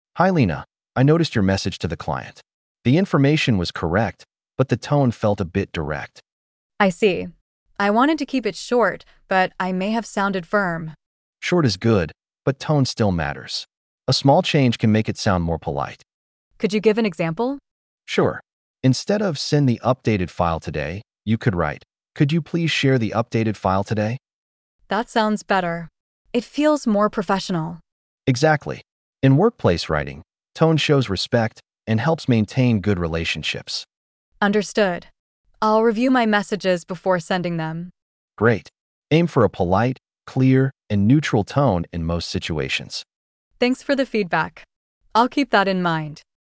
🤝 A manager gives feedback on tone in workplace messages.